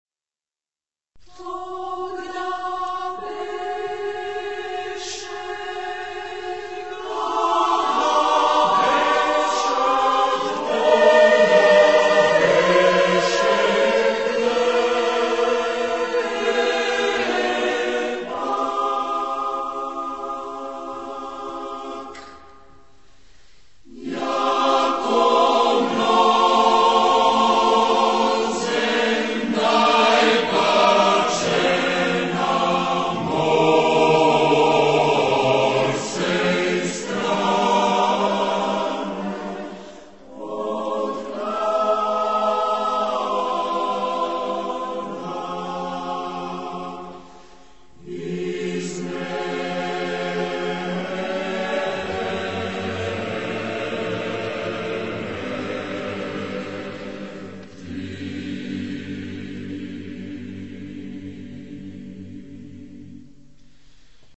Genre-Style-Form: Secular ; Madrigal
Mood of the piece: sorrowful ; calm
Type of Choir: SATTBB  (6 mixed voices )
Tonality: polymodal ; modal